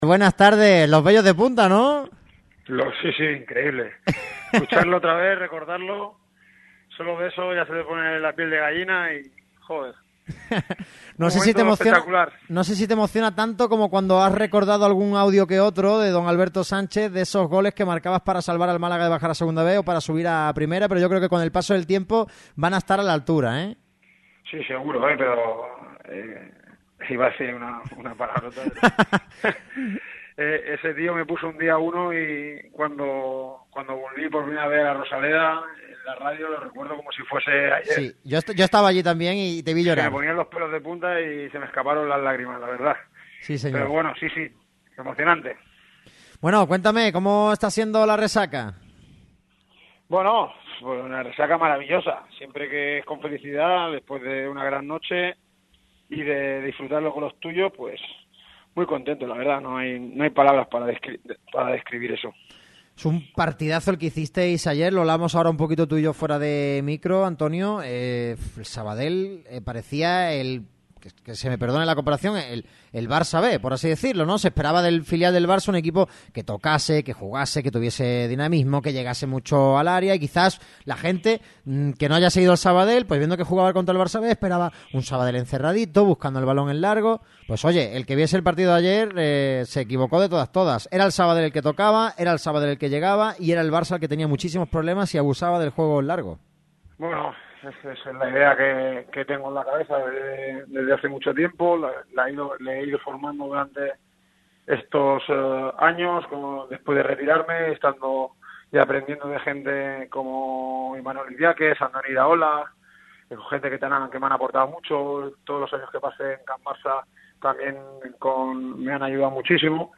El míster intervino en la sintonía de Radio Marca Málaga.
Antonio Hidalgo intervino en los micrófonos de Radio Marca Málaga para celebrar la hazaña del cuadro catalán derrotando al Barça B sobre el verde del Lorenzo Cuevas en Marbella. Cinco años después vuelven al fútbol profesional junto a Logroñés, Cartagena y Castellón.